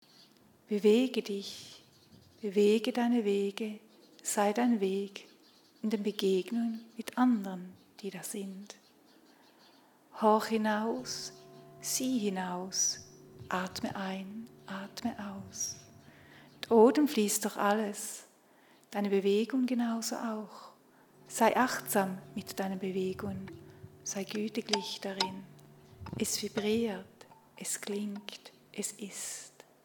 Momentum-Aufnahmen